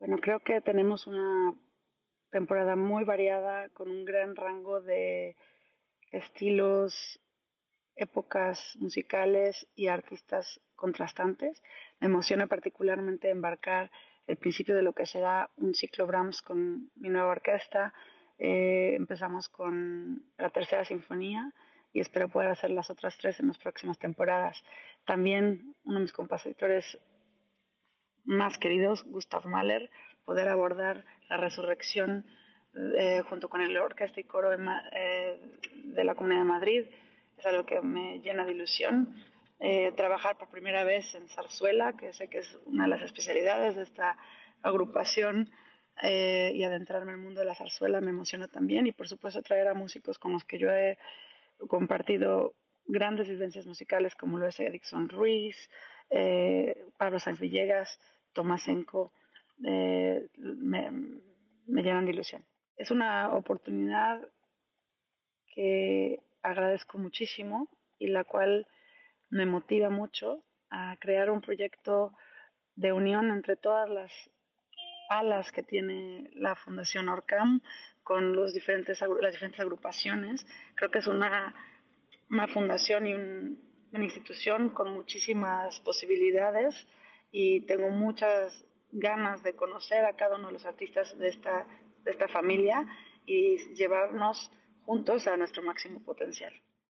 Declaraciones de Alondra de la Parra
Declaraciones Alondra ORCAM.mp3